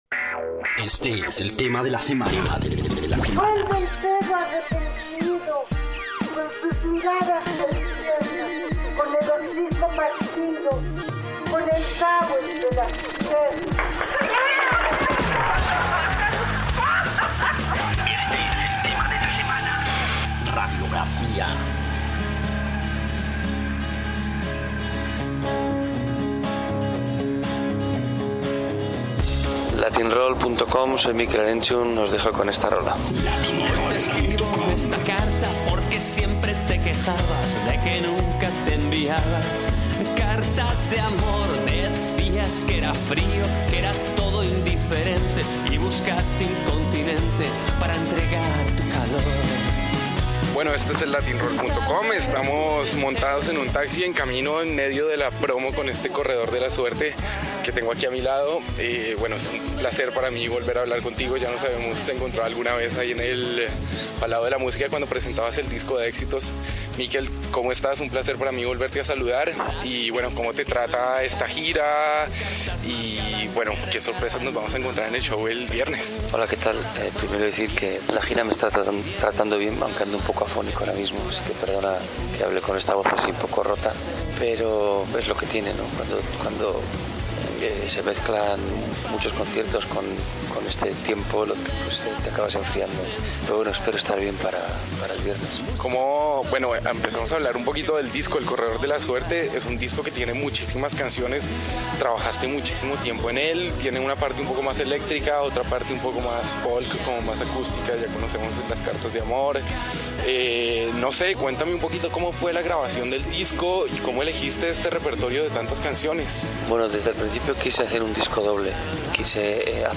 Latin-Roll - Entrevistas Mikel Erenxtun Reproducir episodio Pausar episodio Mute/Unmute Episode Rebobinar 10 segundos 1x Fast Forward 30 seconds 00:00 / Suscribir Compartir Feed RSS Compartir Enlace Incrustar